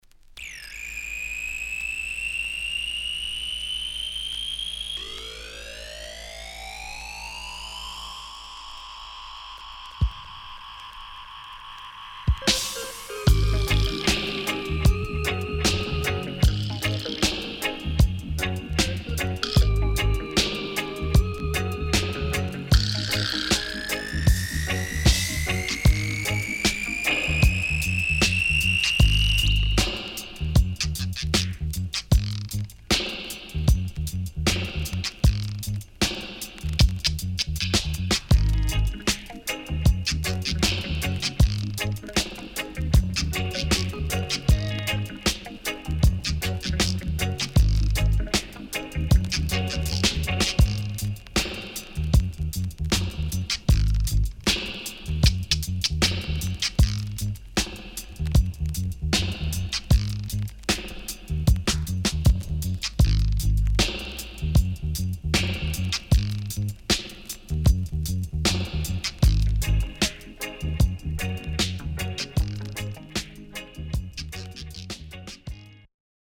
HOME > DUB